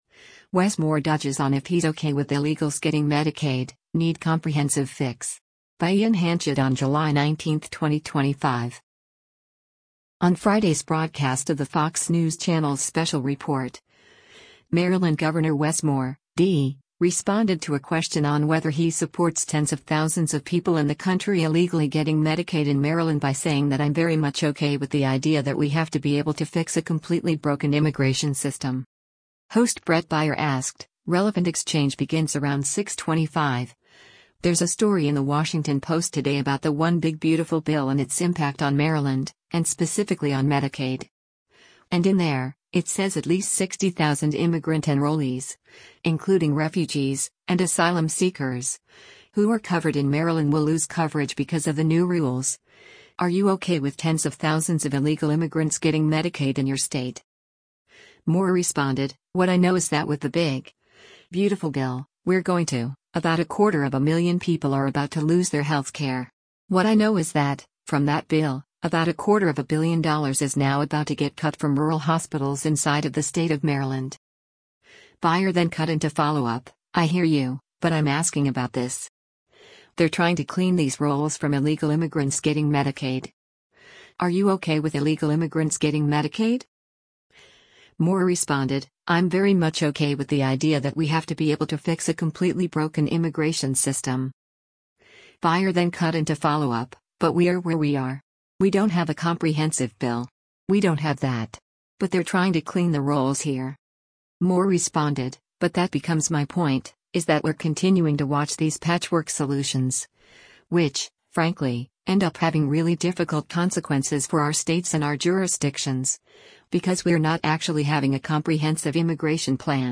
On Friday’s broadcast of the Fox News Channel’s “Special Report,” Maryland Gov. Wes Moore (D) responded to a question on whether he supports tens of thousands of people in the country illegally getting Medicaid in Maryland by saying that “I’m very much okay with the idea that we have to be able to fix a completely broken immigration system.”